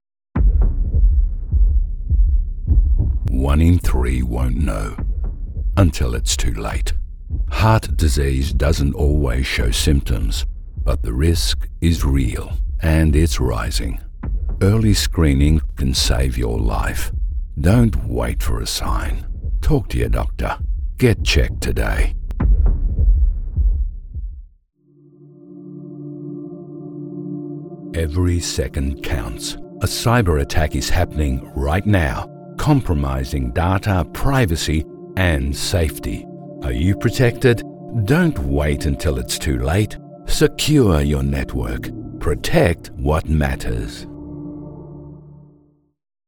E=learning, Corporate & Industrial Voice Overs
Older Sound (50+)